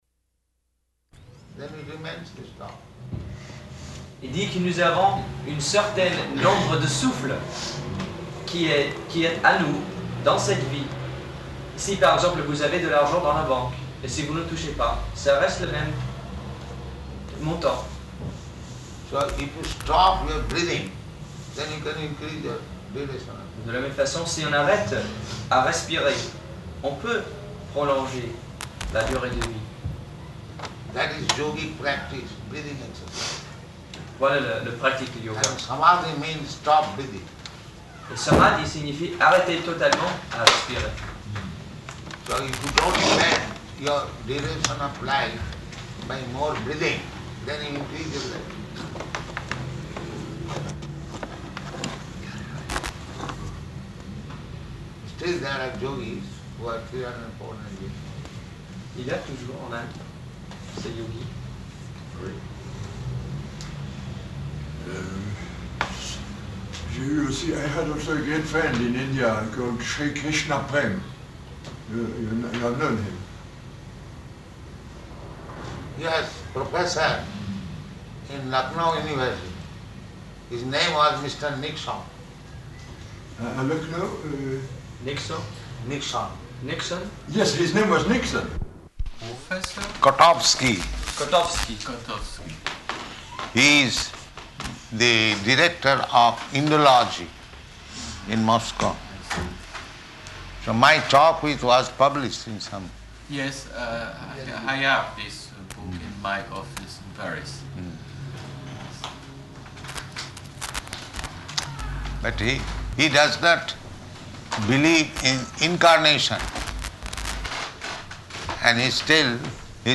Room Conversation with Russian Orthodox Church Representative
Type: Conversation
Location: Paris